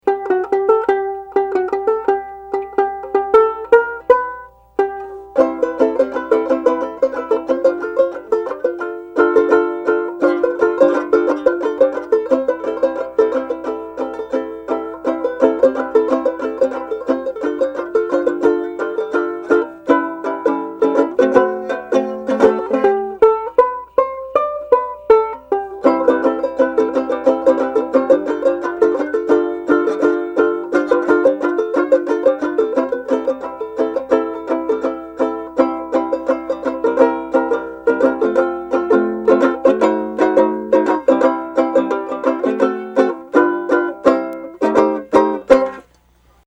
Contrary to the usual description, the Dixie banjo ukulele appears to be made of cast zinc with chrome plating.
dixie banjo uke.mp3